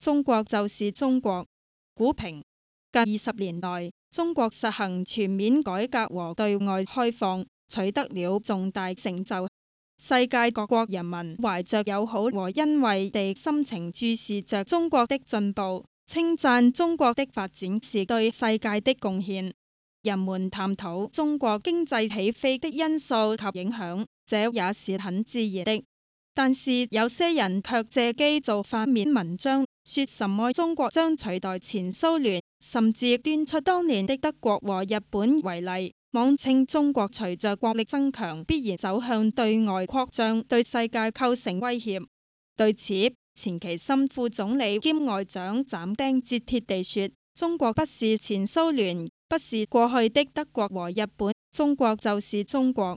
These links point to the three synthetic wave files, of which the first one was generated by our KBCT2.0 Cantonese TTS engine, the next one was generated by our KBCE2.0 Chinese-English Mixed-lingual TTS engine, and the last one was generated by our KD2000 Chinese TTS engine.